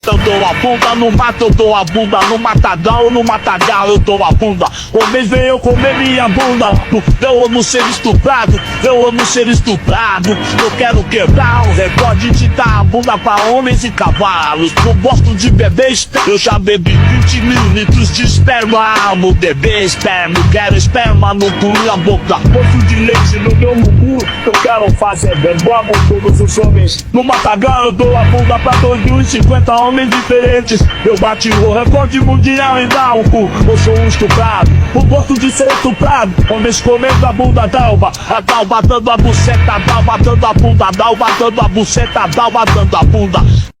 2025-04-23 14:47:55 Gênero: Rap Views